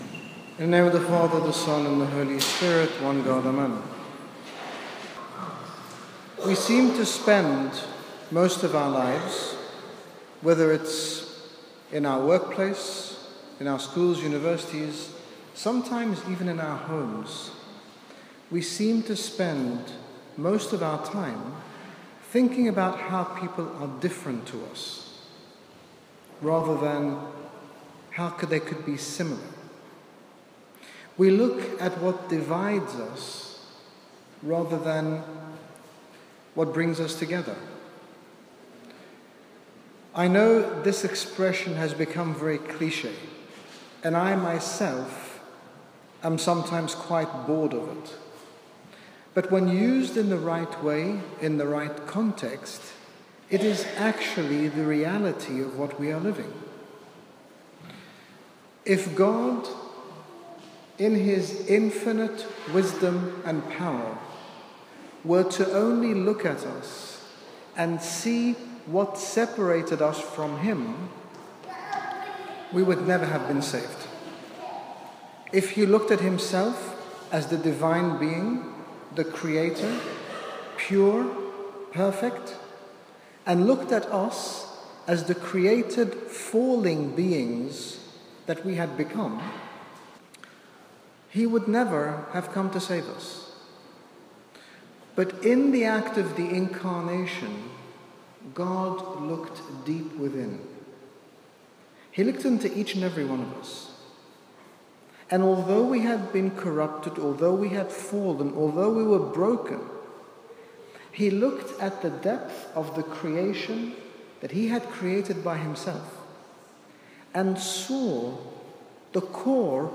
His Grace Bishop Angaelos, General Bishop of the Coptic Orthodox Church in the United Kingdom, speaks about unity and division, highlighting the fact that when we abide in Christ there is no room for conflict or division as we are all called to unity with one another, within ourselves and with God.